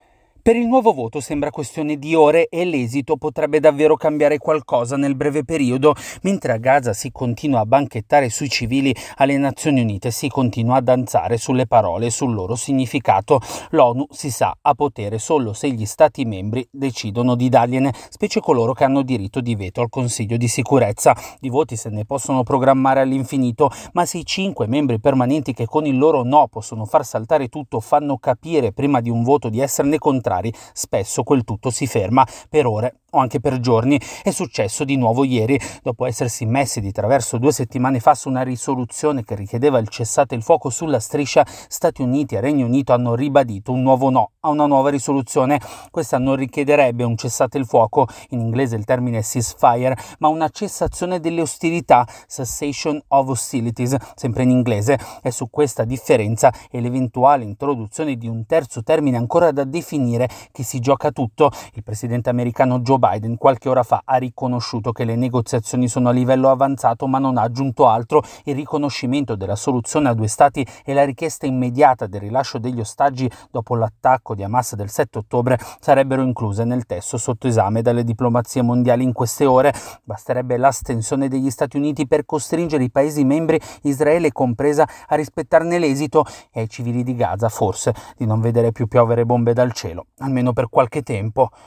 Nelle prossime ore, a partire dalle 22 italiane, a New York il consiglio di sicurezza dell’Onu tenterà nuovamente di votare una risoluzione per chiedere una sospensione dei combattimenti dopo tre rinvii consecutivi. Da New York il nostro collaboratore